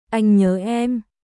Anh nhớ emアイン ニョー エム君（女）が恋しいよ